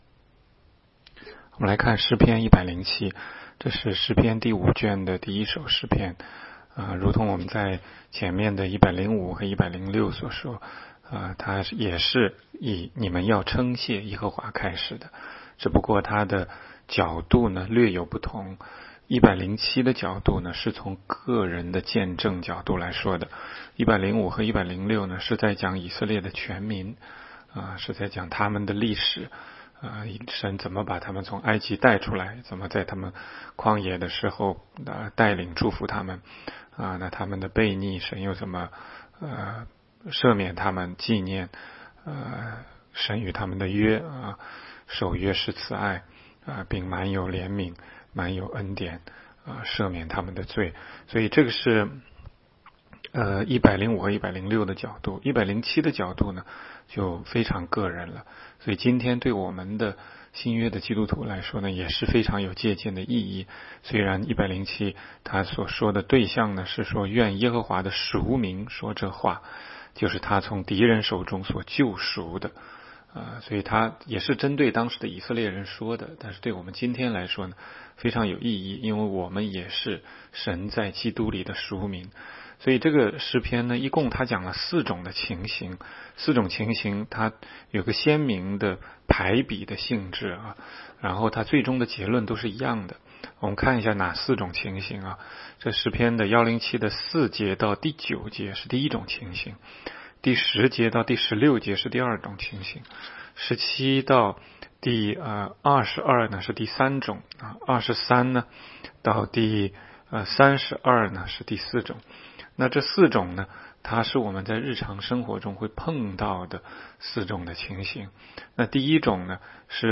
16街讲道录音 - 每日读经 -《 诗篇》107章